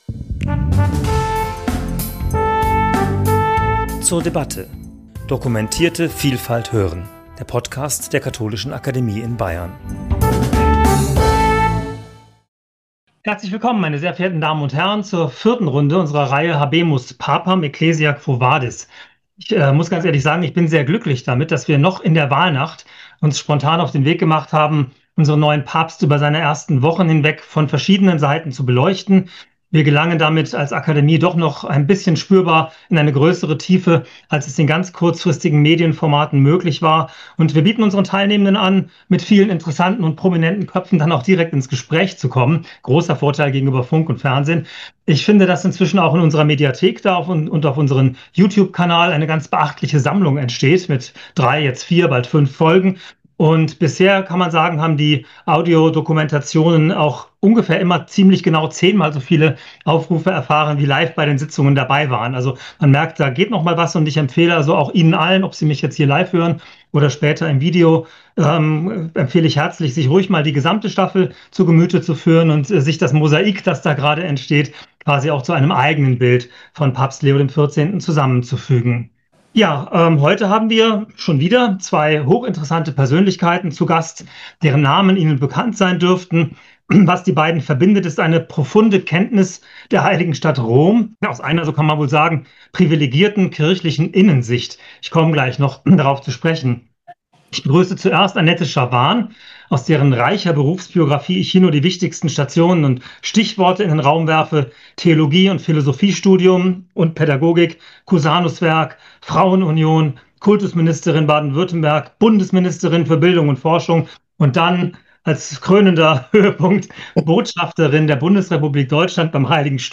Gespräch zum Thema 'Habemus papam! Ecclesia, quo vadis?', Folge 4 ~ zur debatte Podcast